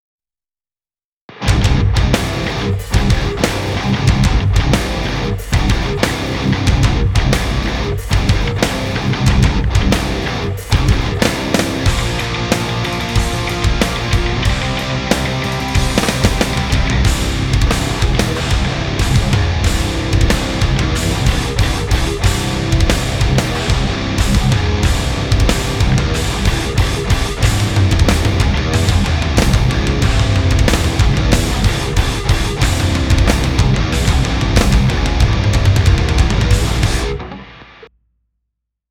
but damn, having compared these two now I gotta say the m160 sounds even better on guitar...less proximity effect (I had them both pretty close to the cab) and more high end...
I just threw the mics in the middle, no tweaking, positioning etc, just a quick rough test.
Beyer m160